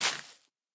Minecraft Version Minecraft Version latest Latest Release | Latest Snapshot latest / assets / minecraft / sounds / block / sponge / break1.ogg Compare With Compare With Latest Release | Latest Snapshot